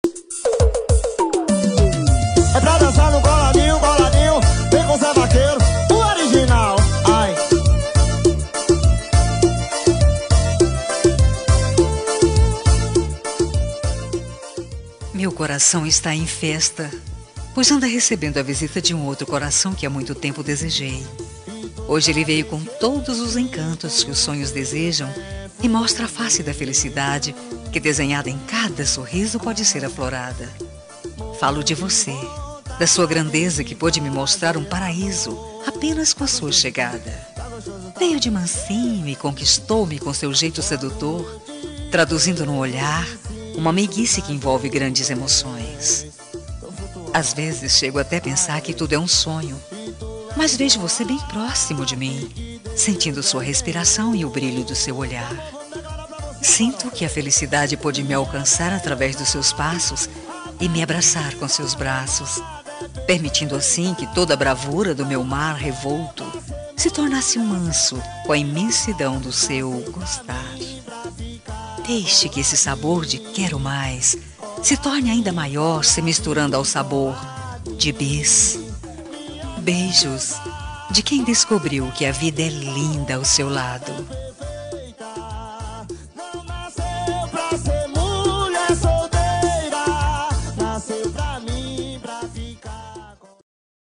Telemensagem Início de Namoro – Voz Feminina – Cód: 6455